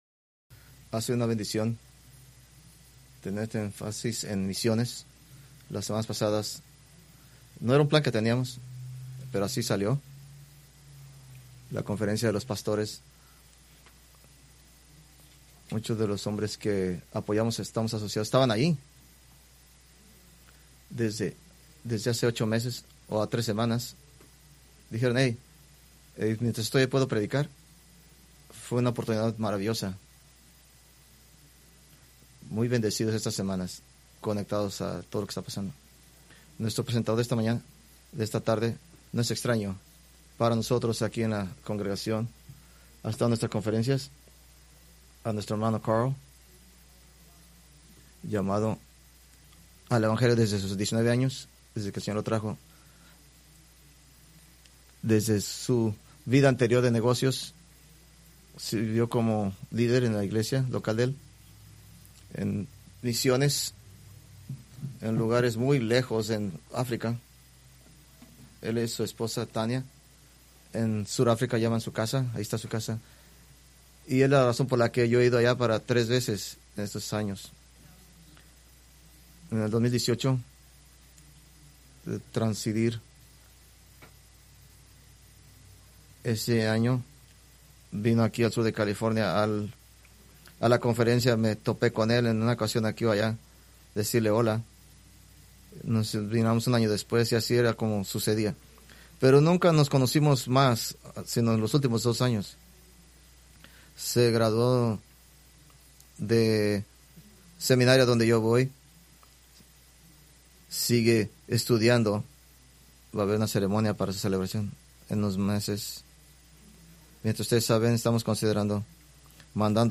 Preached March 15, 2026 from Escrituras seleccionadas